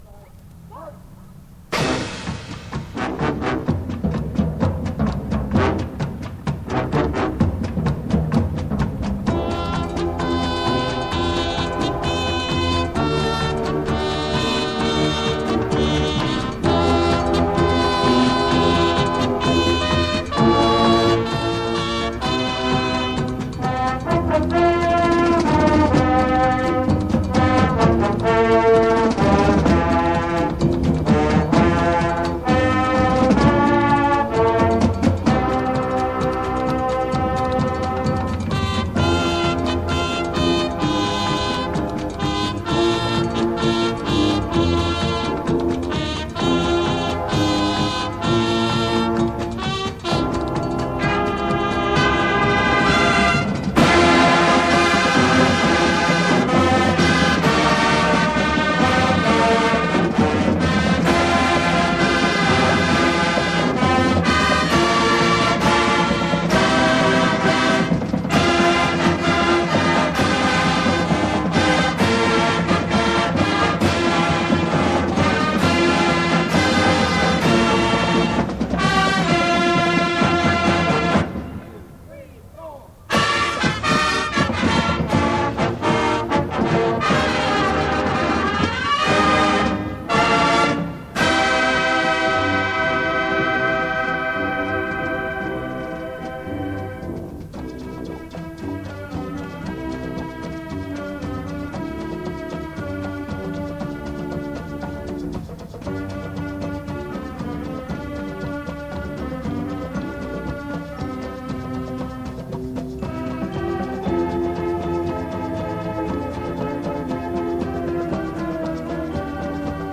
Valdosta High Drum Corps Festival 1974.mp3